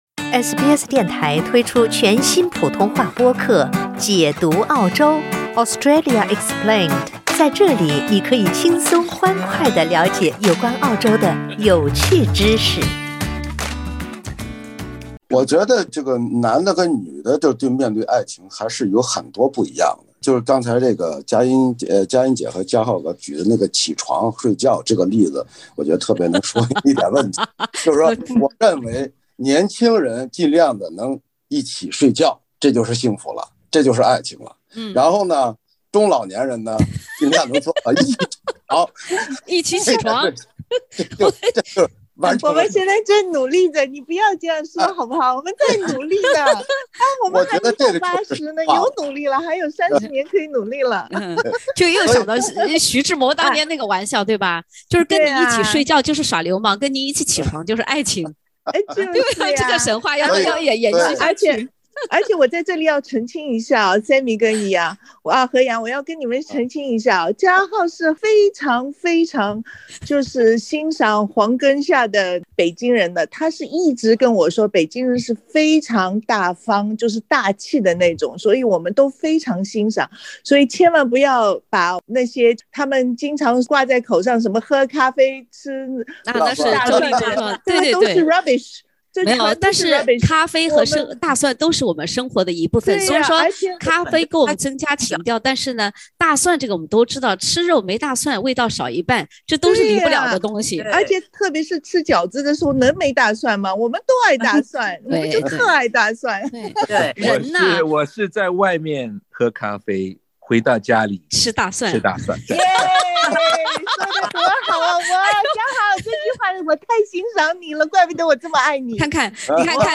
(点击封面图片，收听风趣对话）